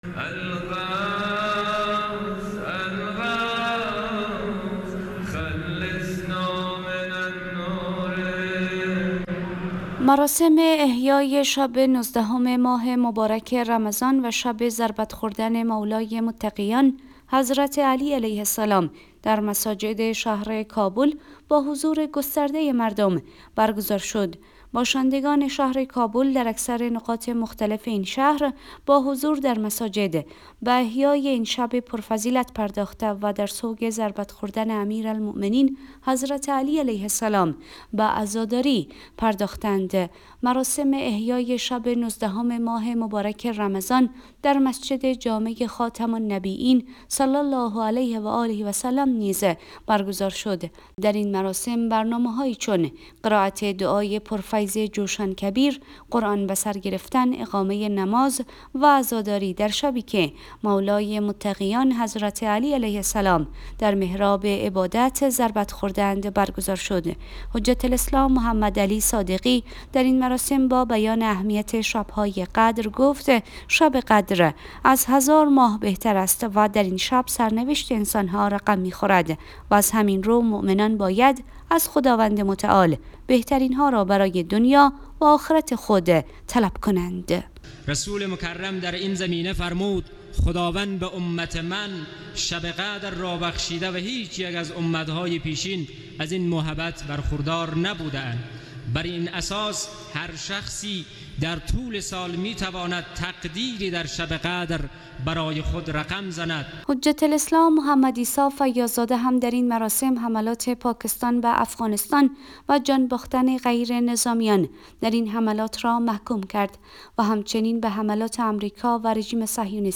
مراسم احیای شب نوزدهم ماه مبارک رمضان و شب ضربت خوردن مولای متقیان حضرت علی علیه‌السلام در مساجد شهر کابل با حضور گسترده مردم برگزار شد.